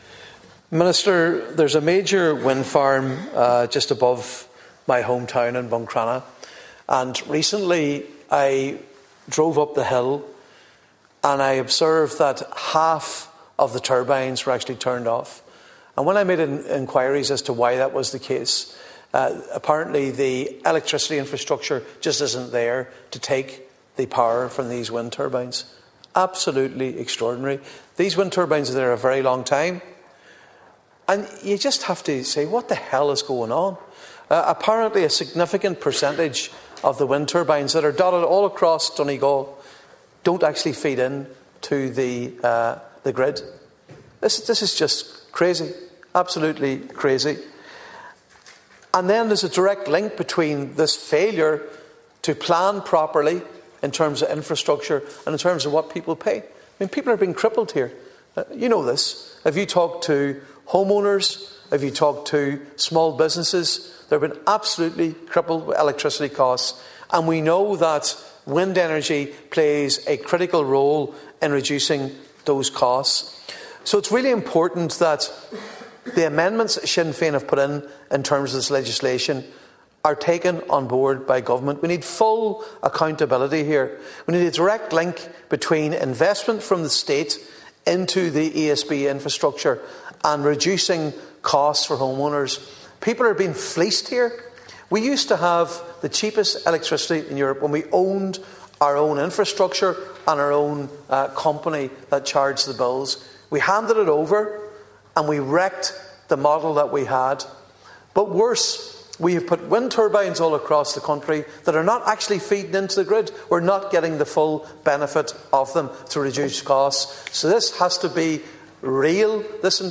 Speaking in the Dáil during a debate on the Electricity Supply Bill, Deputy Mac Lochlainn called on the government to come up with a solution: